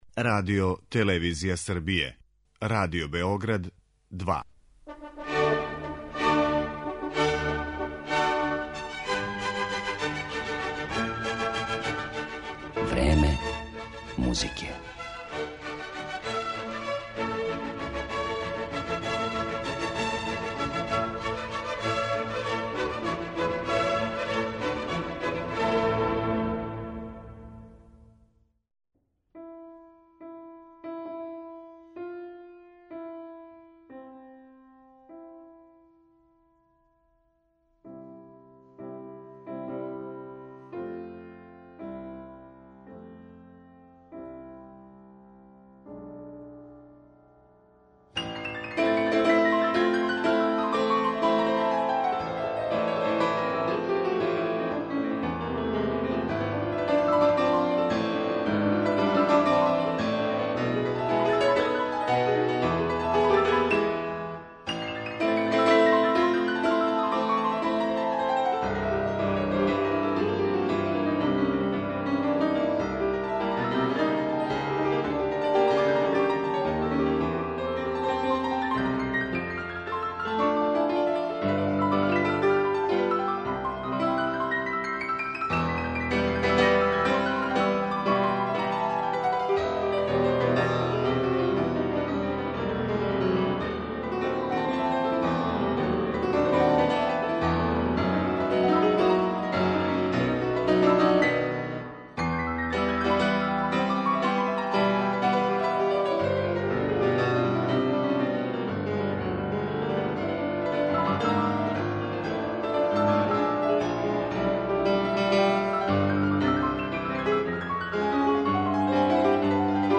Разогвор